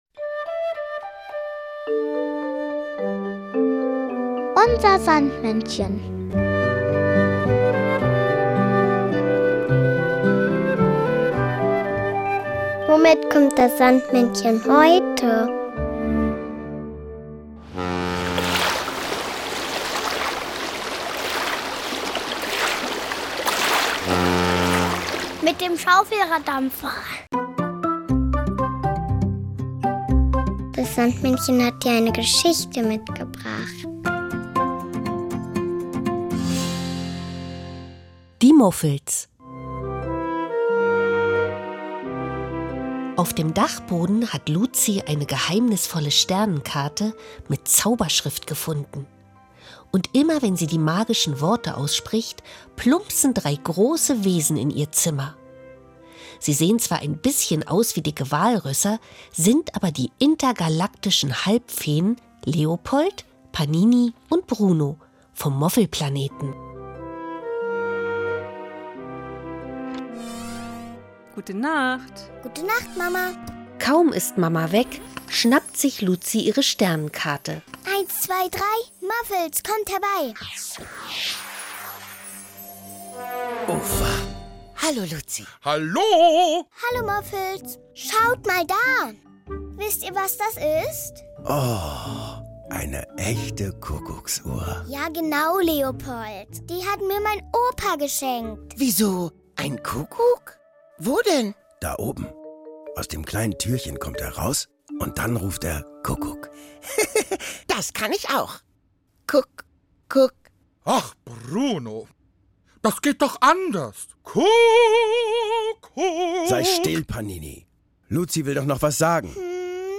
Kinderlied “Biri Baba Bai" von Mukkemacher.